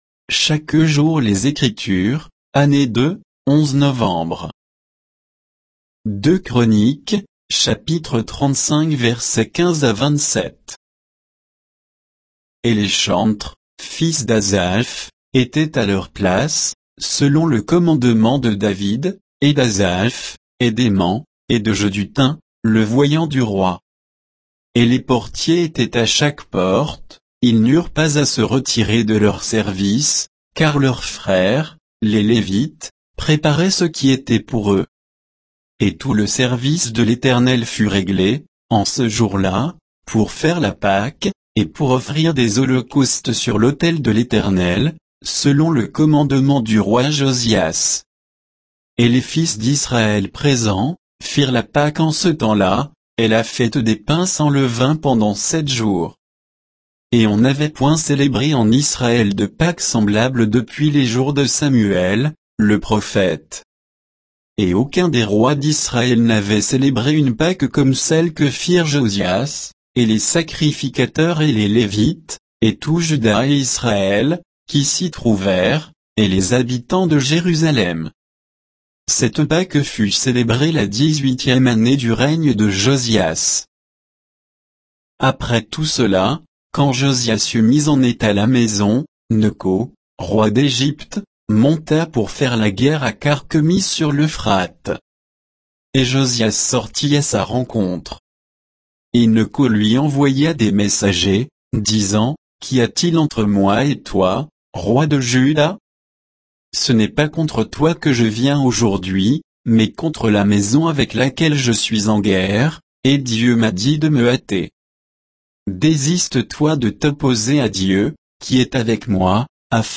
Méditation quoditienne de Chaque jour les Écritures sur 2 Chroniques 35